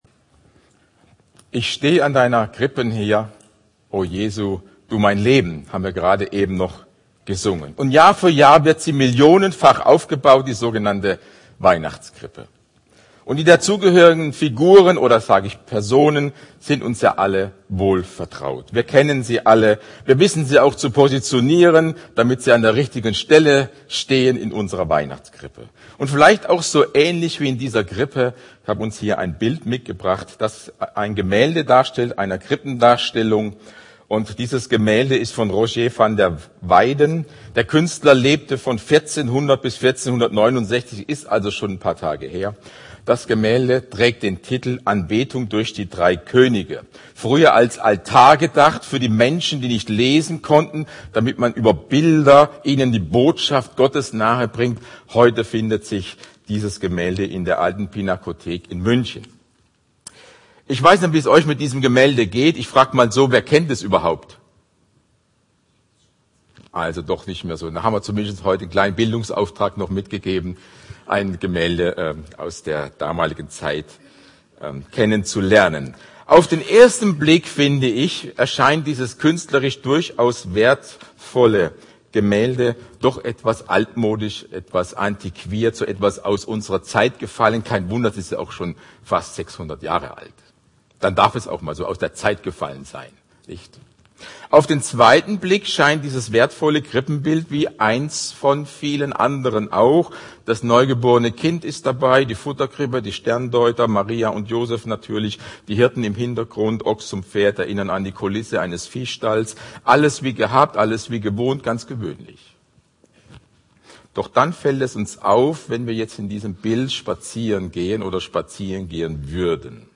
Predigt Mit dem Laden des Videos akzeptieren Sie die Datenschutzerklärung von YouTube.